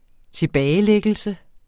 Udtale [ -ˌlεgəlsə ]